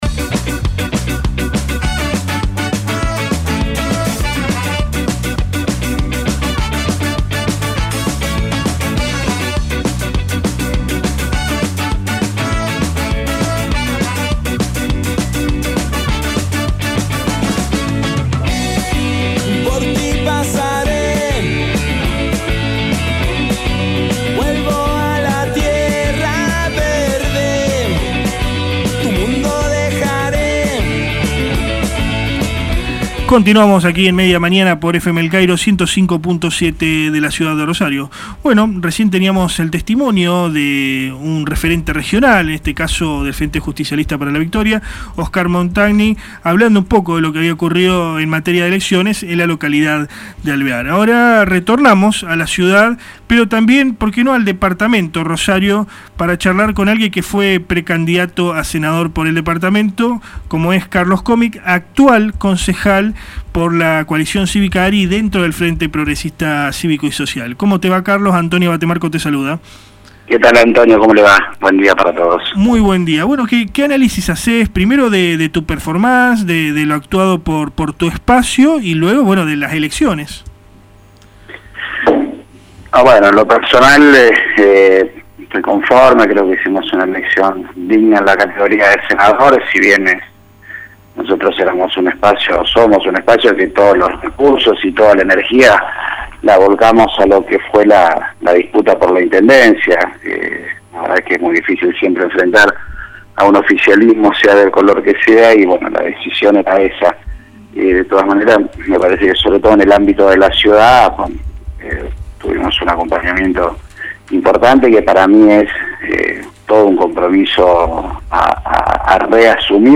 CARLOS COMI AUDIO ENTREVISTA